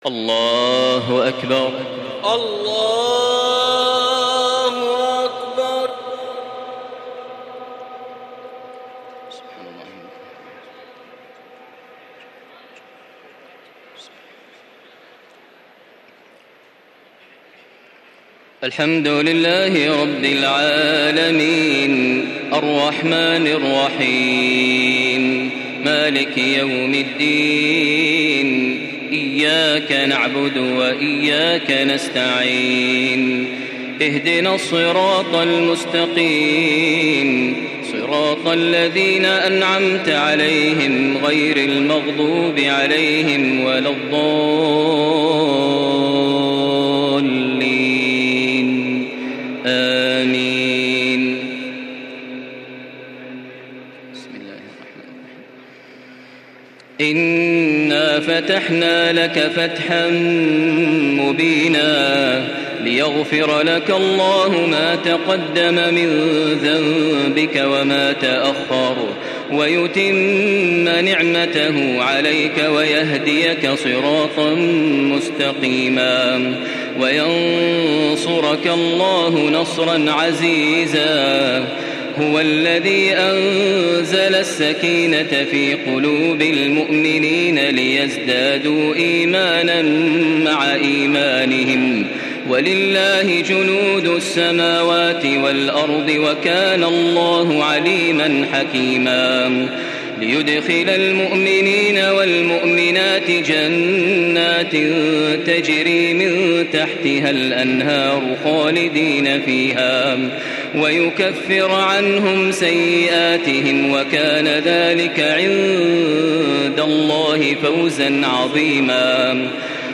تراويح ليلة 25 رمضان 1435هـ من سور الفتح الحجرات و ق Taraweeh 25 st night Ramadan 1435H from Surah Al-Fath and Al-Hujuraat and Qaaf > تراويح الحرم المكي عام 1435 🕋 > التراويح - تلاوات الحرمين